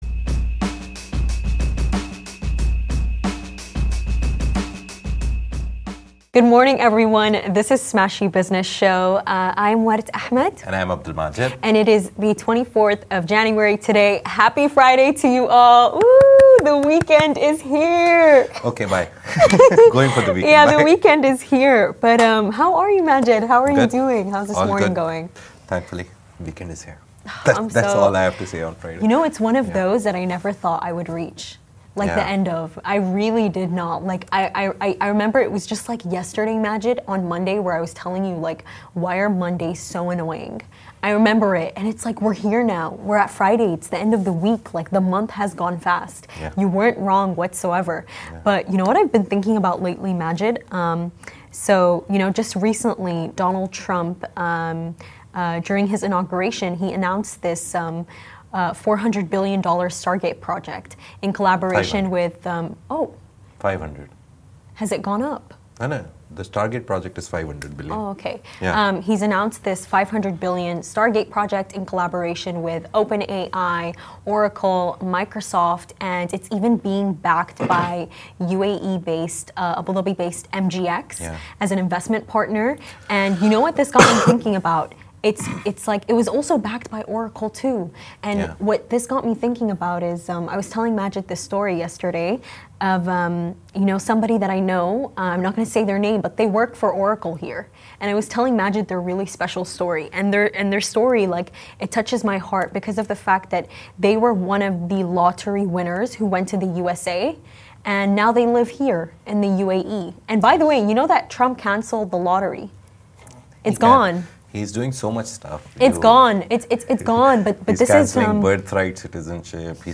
The Smashi Business Show is where Smashi interviews the business leaders who make a difference in this great city.